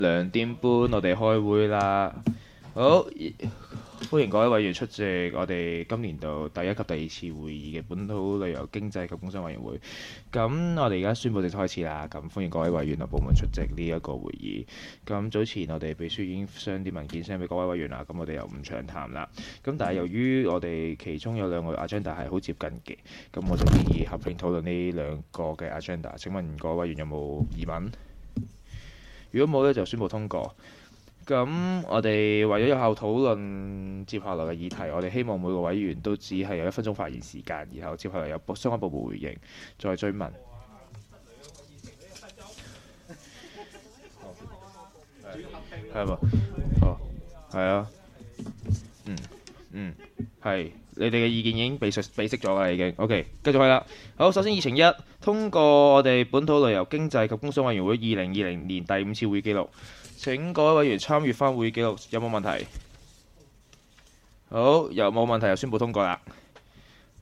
委员会会议的录音记录
地点: 元朗桥乐坊2号元朗政府合署13楼元朗区议会会议厅